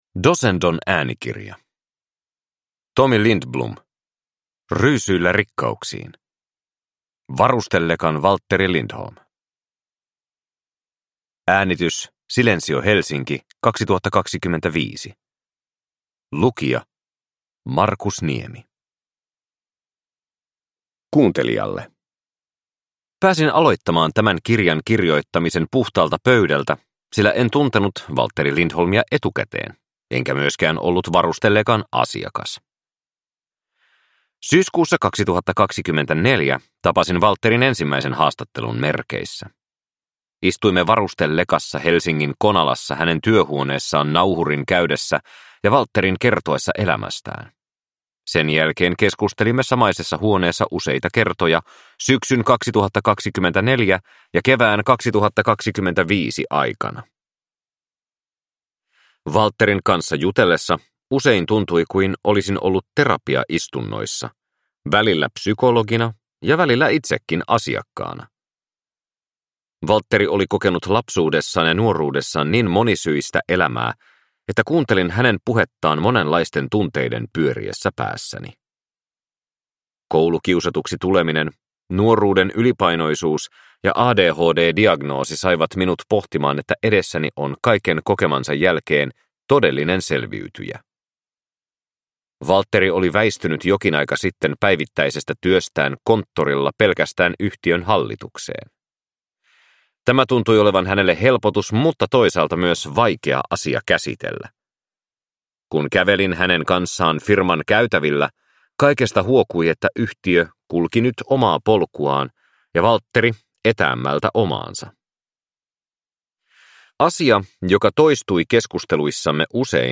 Ryysyillä rikkauksiin (ljudbok) av Tomi Lindblom